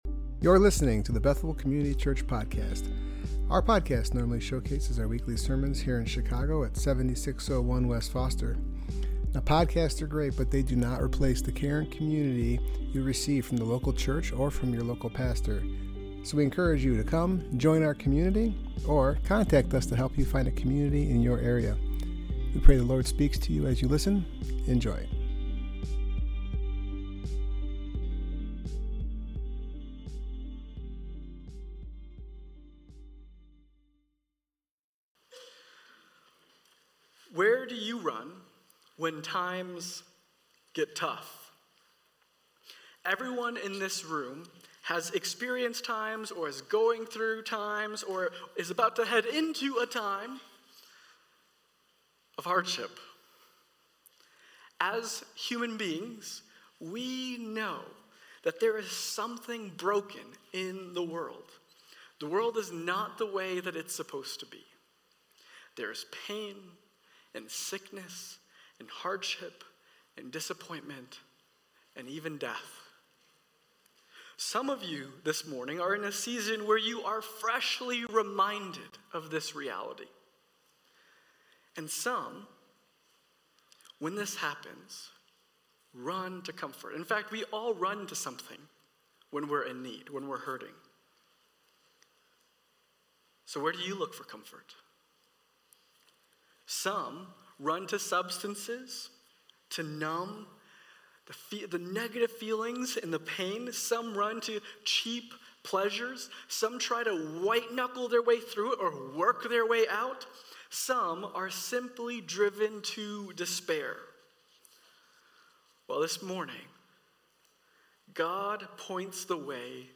Service Type: Worship Gathering